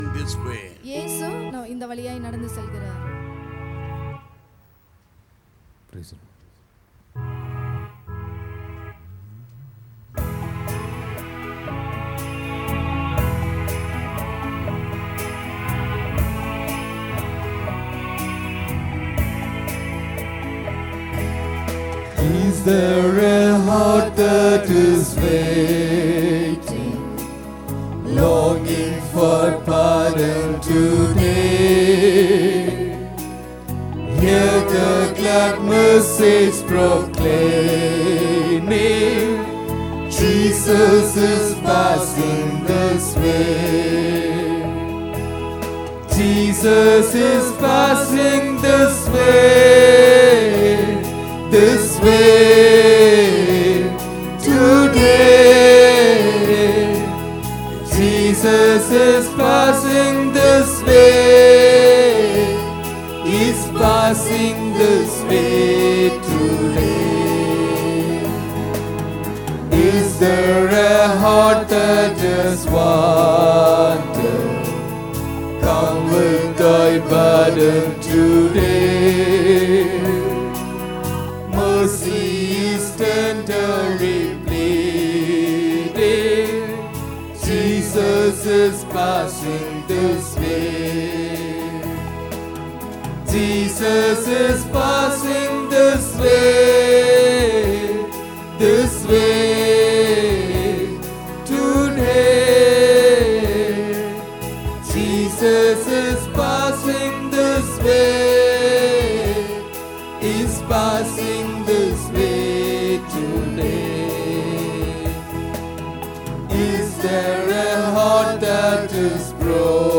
03 Sep 2023 Sunday Morning Service – Christ King Faith Mission